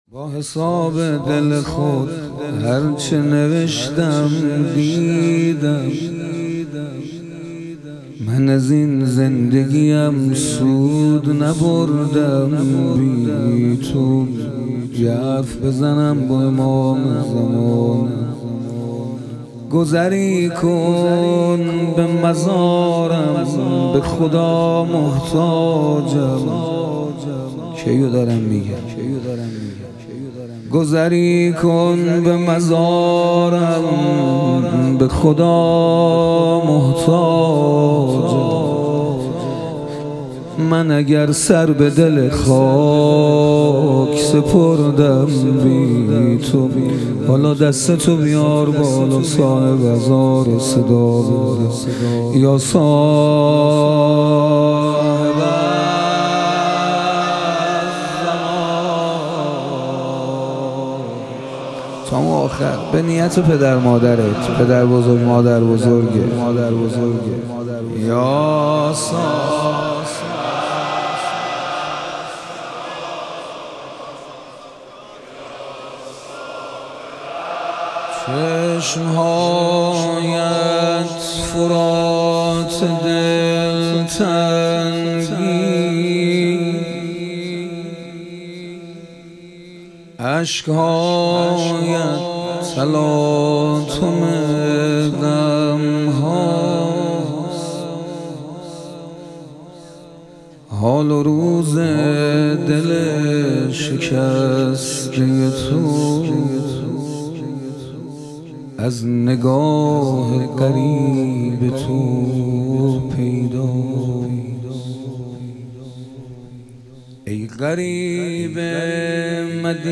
شهادت امام هادی علیه السلام - روضه